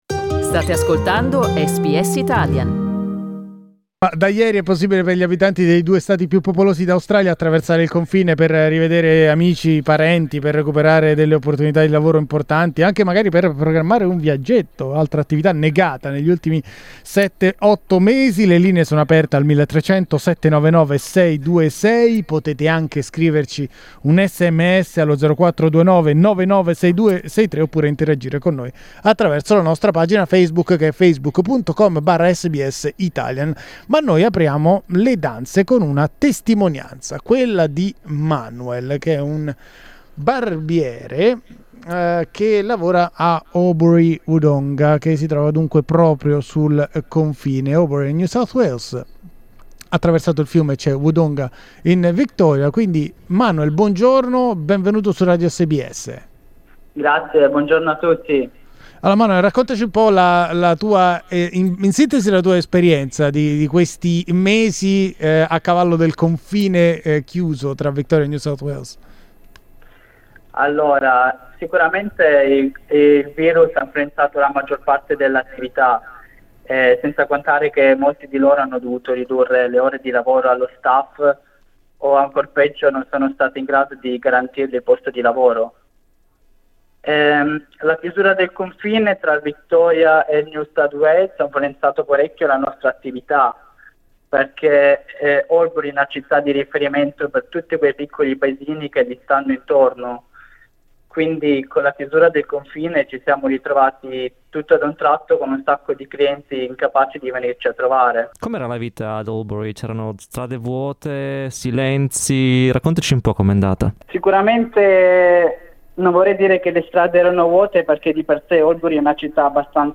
Questa mattina abbiamo aperto le linee telefoniche ai nostri ascoltatori per sapere come stanno vivendo questo momento di ritrovata libertà.